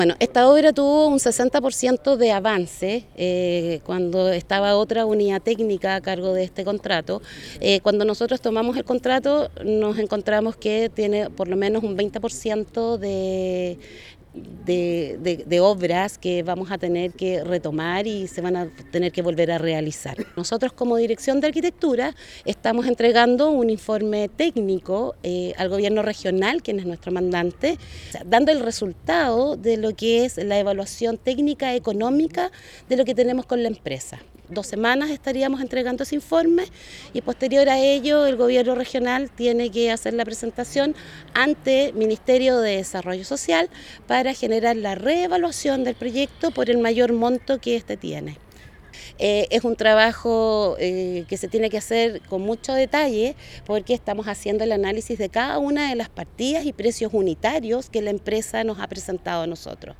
La Directora Regional de Arquitectura, señaló que se está realizando un informe donde se ha evidenciado el deterioro que tienen las obras, lo que será entregado al Consejo Regional para su reevaluación.